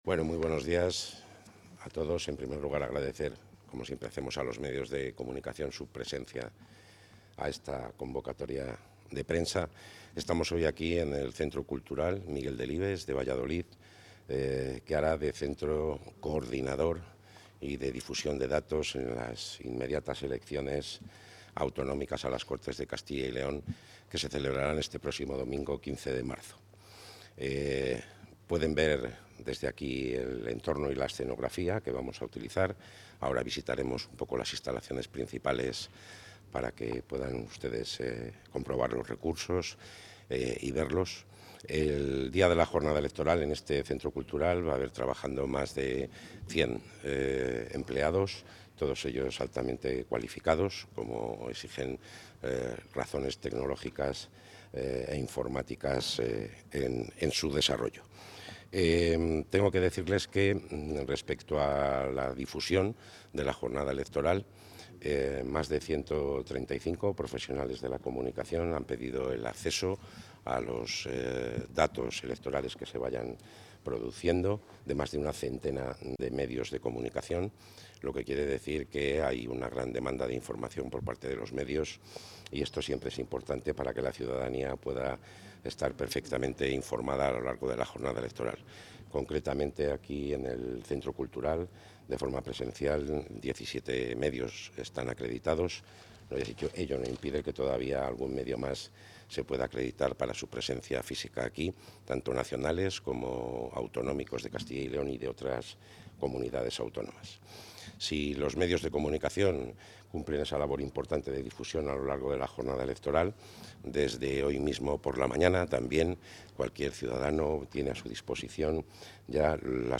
Intervención del consejero Visita al Centro de Procesamiento de Datos Visita al Centro de Procesamiento de Datos Visita al Centro de Procesamiento de Datos Centro de Procesamiento de Datos Centro de Procesamiento de Datos